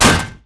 metalmediumbashmetal2.wav